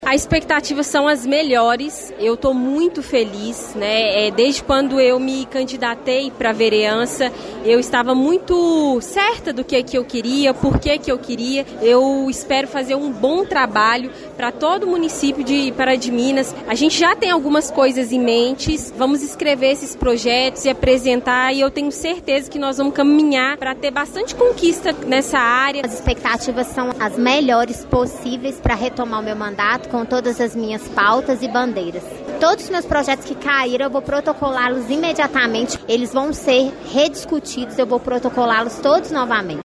Em conversa com o Jornal da Manhã, os vereadores se mostraram animados com o começo dos trabalhos. Camila Gonçalves e Márcia Marzagão, duas das três representantes femininas na câmara, contaram que já tem diversos planos para este novo ano: